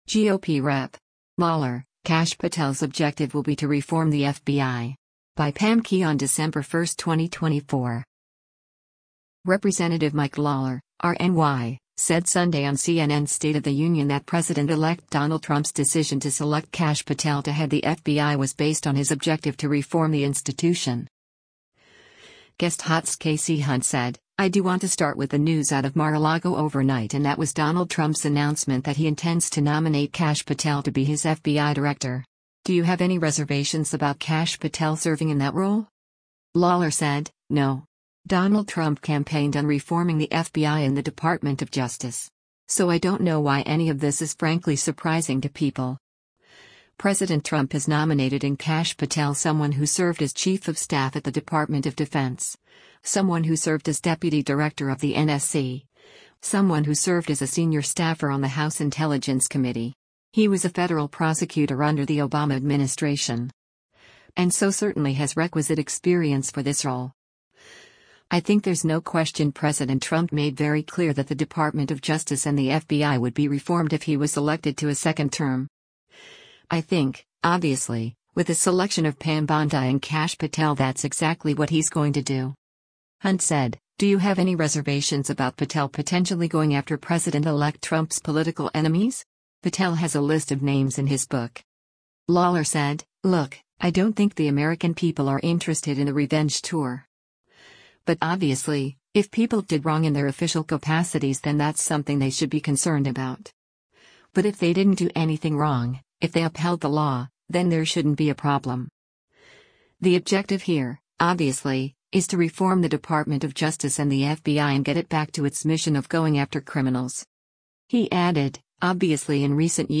Representative Mike Lawler (R-NY) said Sunday on CNN’s “State of the Union” that President-elect Donald Trump’s decision to select Kash Patel to head the FBI was based on his objective to reform the institution.